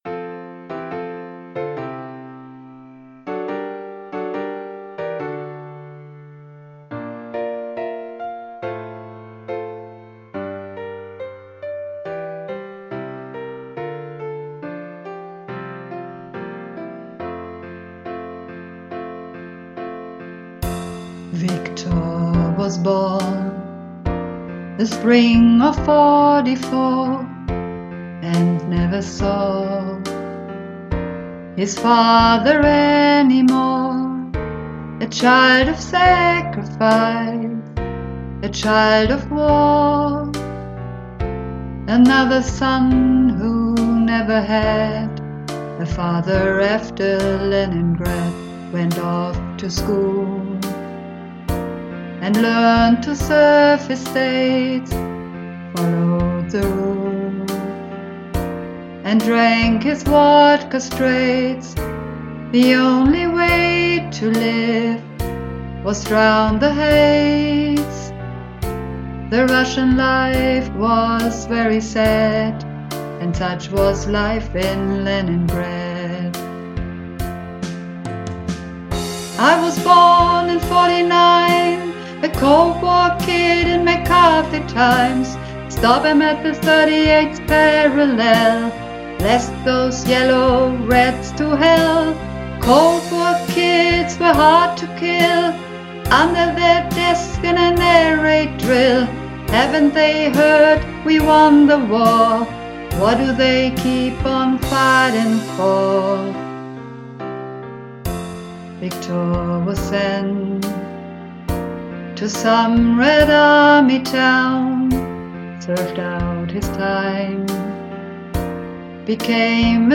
Leningrad (Mehrstimmig)
Leningrad__3_Mehrstimmig.mp3